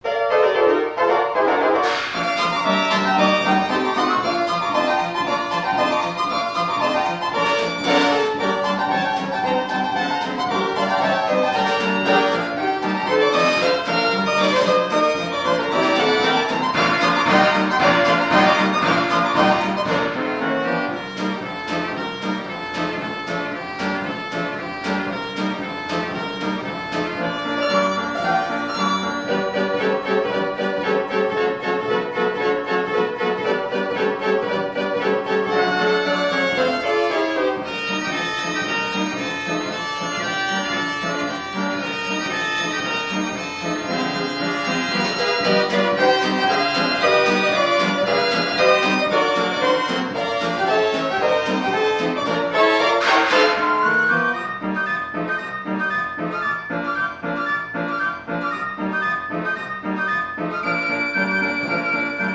* WEBER OTERO ORCHESTRION